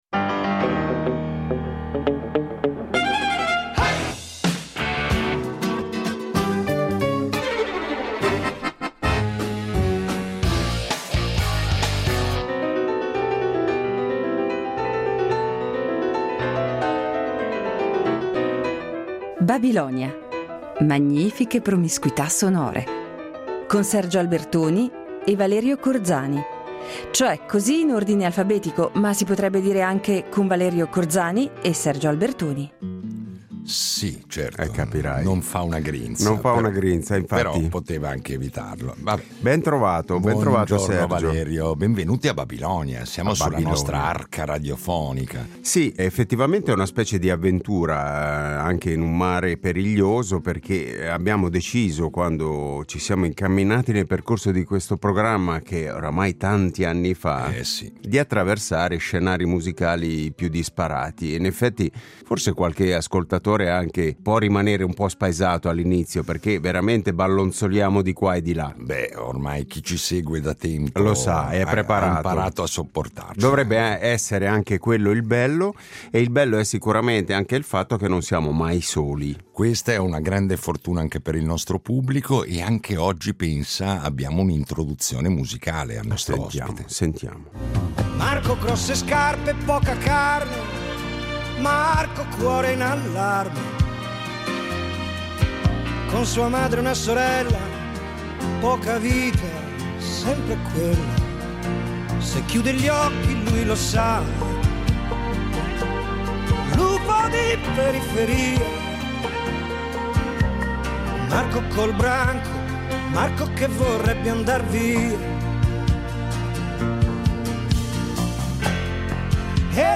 Incontro con un jazzista italiano